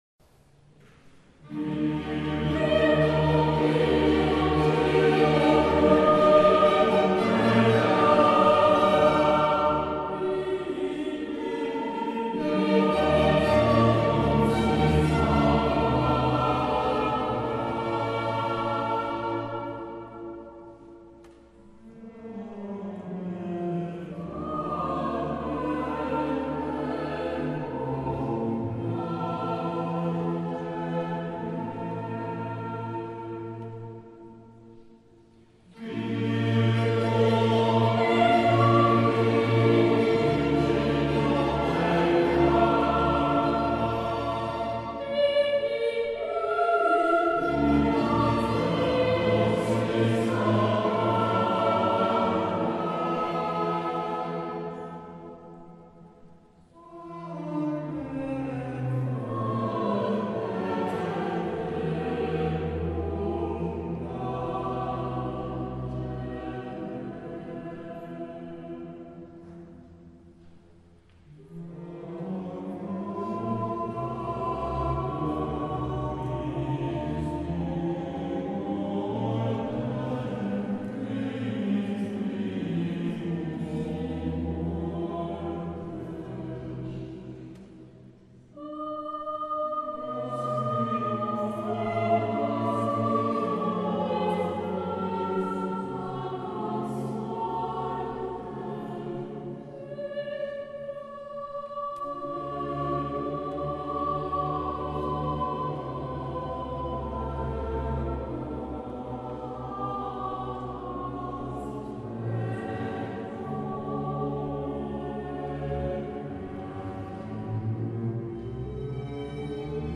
Choir Music